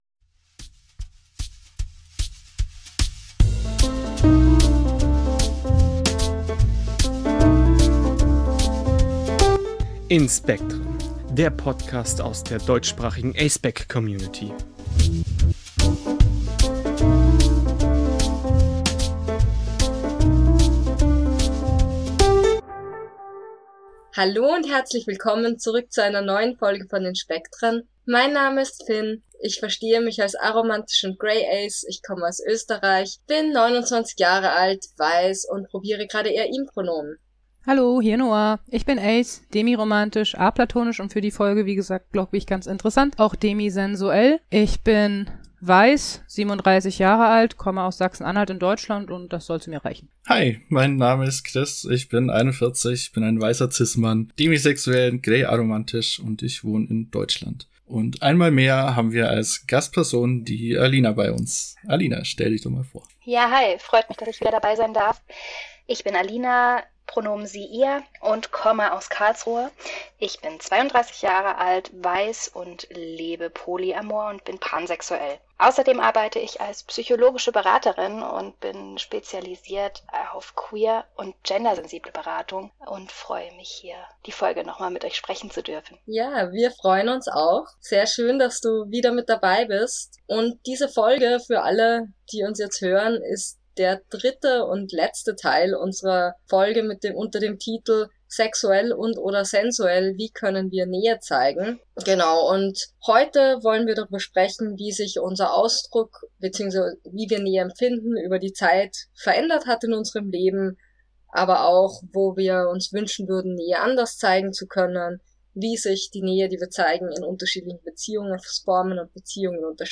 Ganze Folge: Gespräch über verschiedene Formen von Sexualität und Sensualität